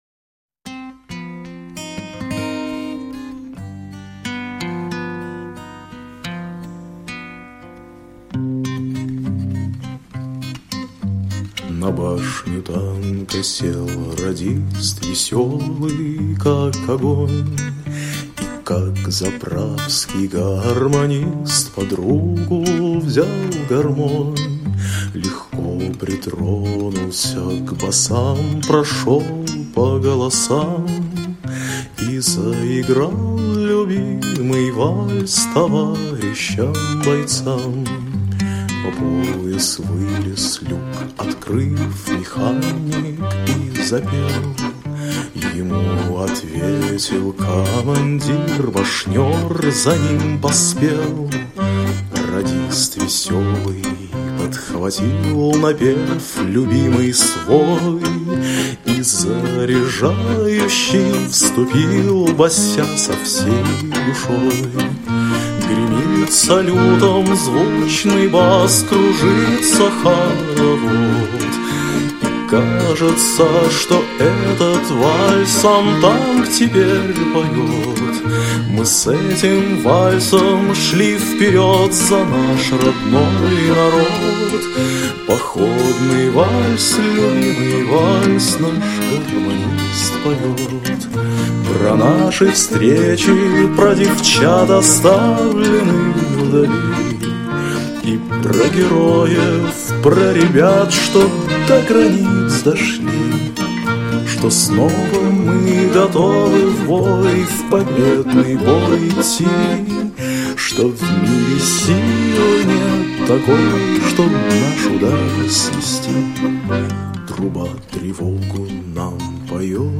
альбом народных песен времен Второй мировой